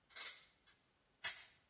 重新加载和触发古董金属枪。